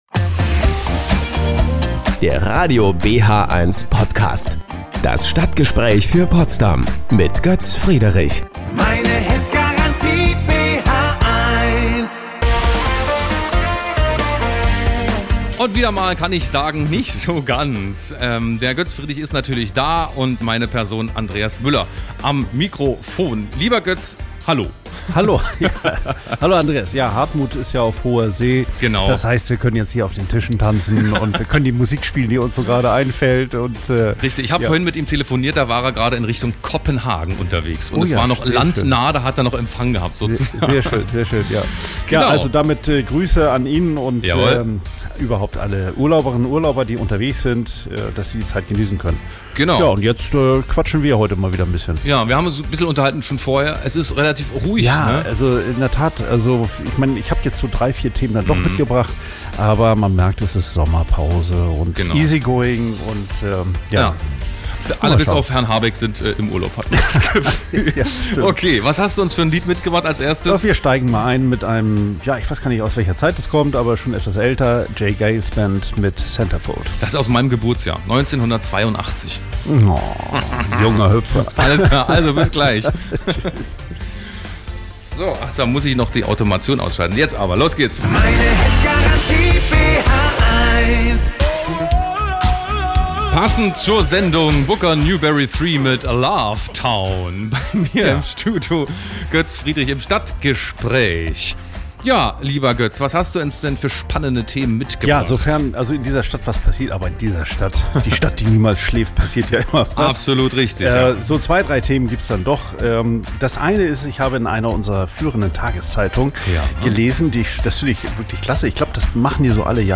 Ein Interview bei Radio BHeins: